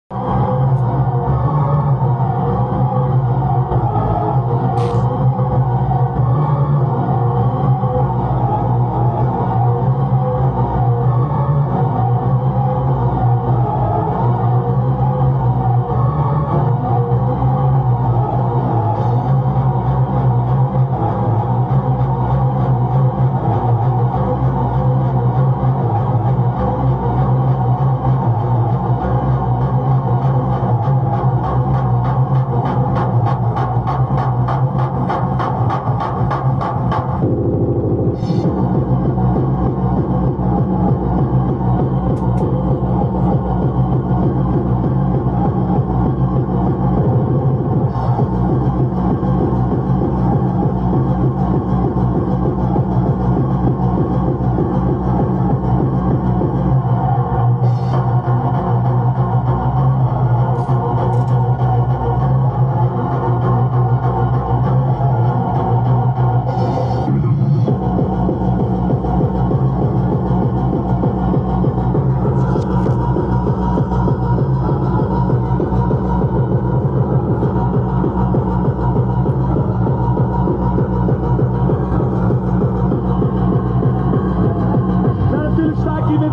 Thunderdome/Gabber.
Swego czasu były mocne imprezki w Holandii w stylu hardcore techno.
Jeden jest w pliku MP3, niestety jakość nie porywa.